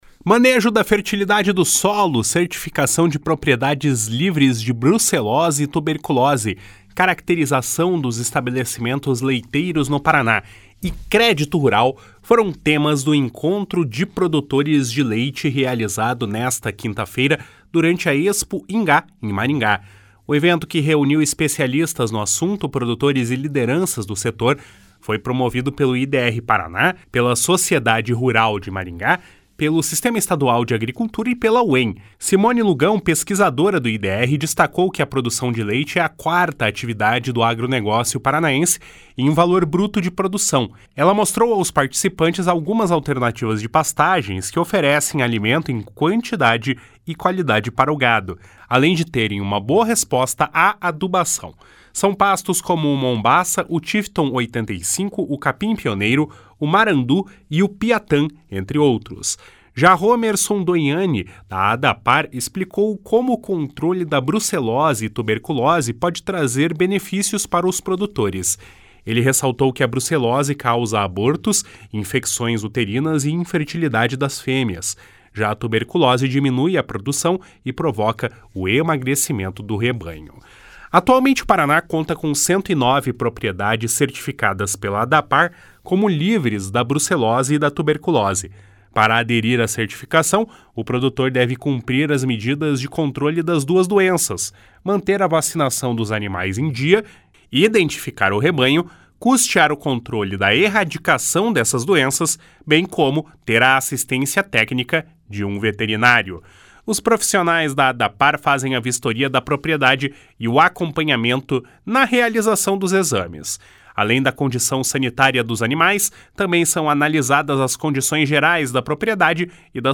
Manejo da fertilidade do solo, certificação de propriedades livres de brucelose e tuberculose, caracterização dos estabelecimentos leiteiros no Paraná e crédito rural foram temas do Encontro de Produtores de Leite realizado nesta quinta-feira, durante a Expoingá, em Maringá.